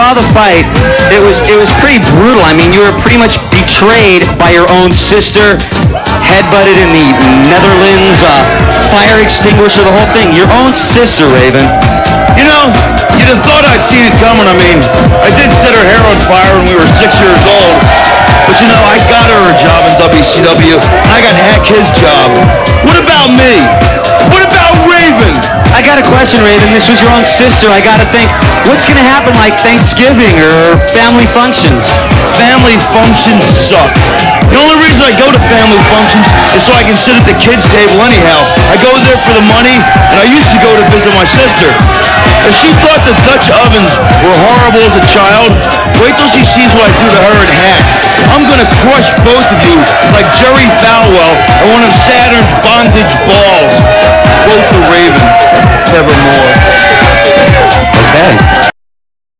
- This was an interview conducted by Rikki Rachtmann at a Nitro Party with Raven on [3.16.99], the day after Raven's loss at WCW Uncensored.